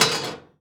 metal_object_small_move_impact_03.wav